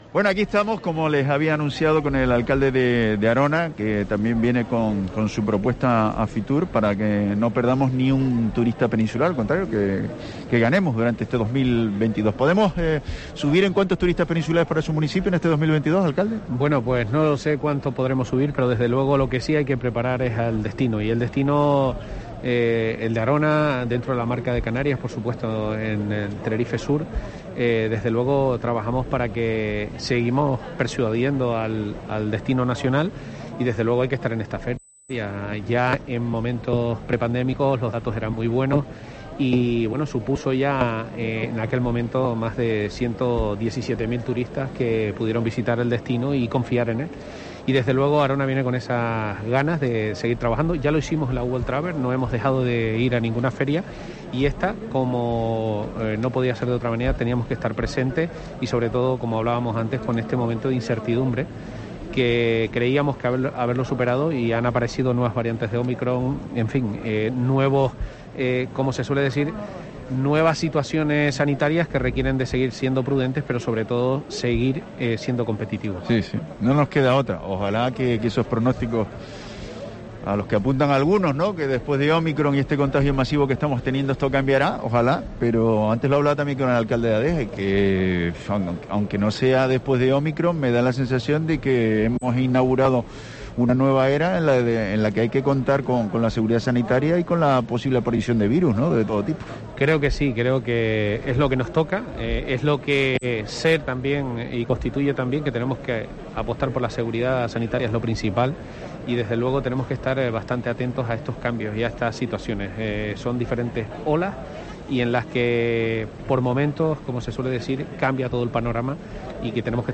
Entrevista a José Julián Mena, alcalde de Arona, en Fitur